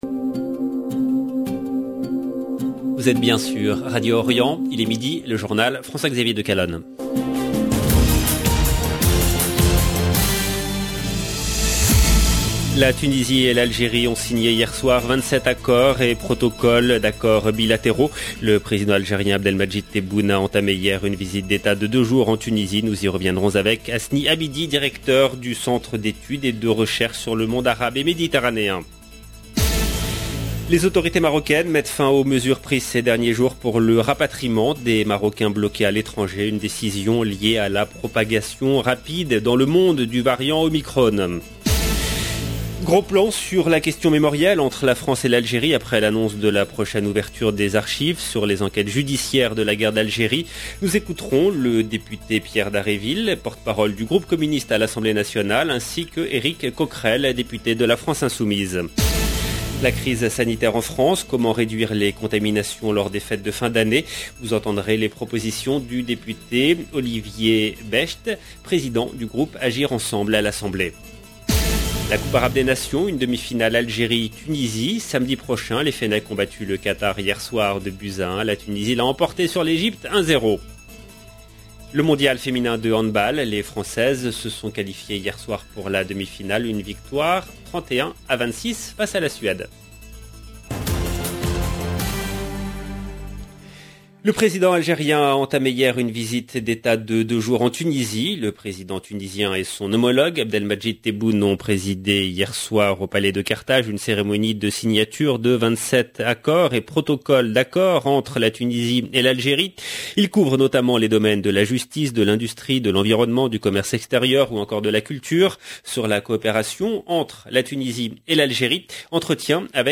Nous écouterons le député Pierre Dharréville, porte parole du groupe Communiste à l’Assemblée nationale et Eric Coquerel, Député la France insoumise.
Nous écouterons les propositions du député Olivier Becht, Président du groupe Agir Ensemble à l’assemblée nationale.